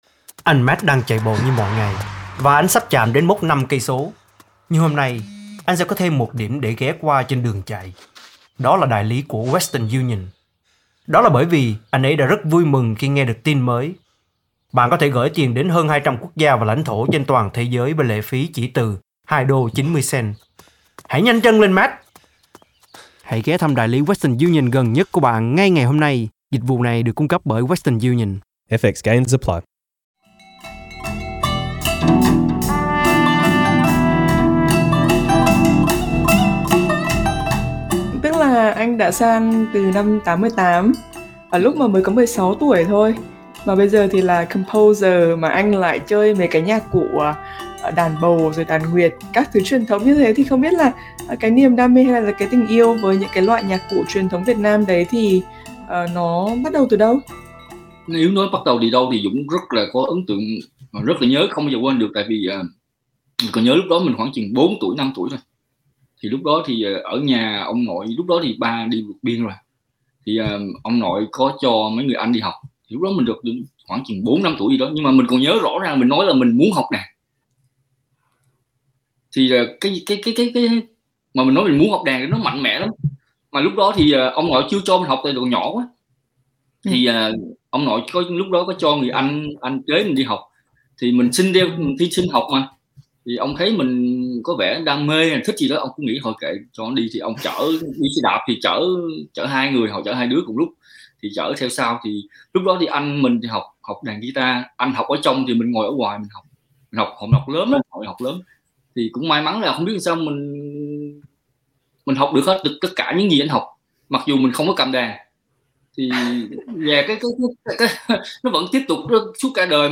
Mời quý vị bấm vào hình để nghe toàn bộ nội dung cuộc trò chuyện.